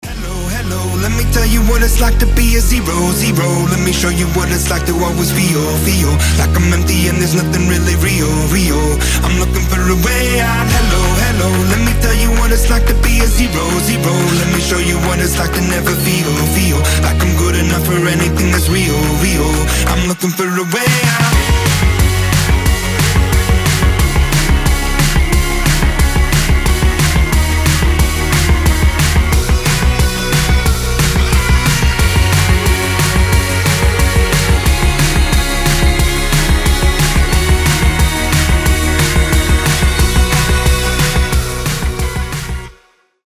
• Качество: 320, Stereo
ритмичные
Electronic Rock
indie rock
бодрые